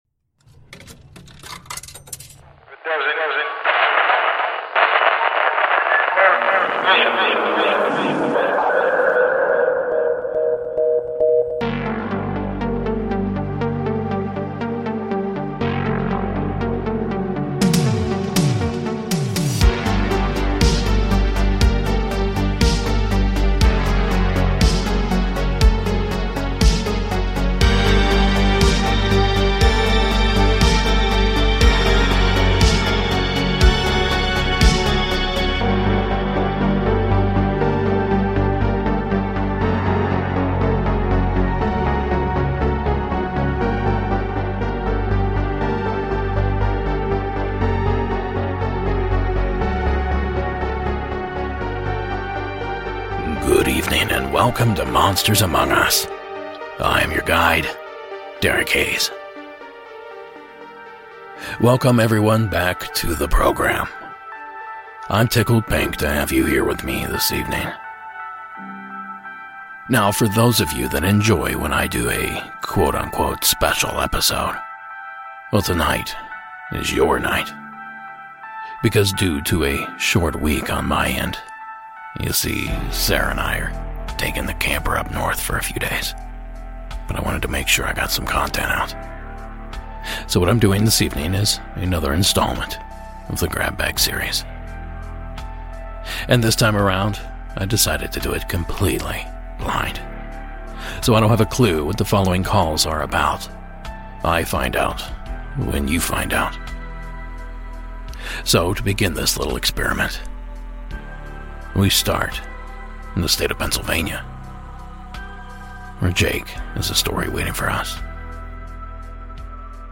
Season 13 Episode 5 of Monsters Among Us Podcast, true paranormal stories of ghosts, cryptids, UFOs and more told by the witnesses themselves.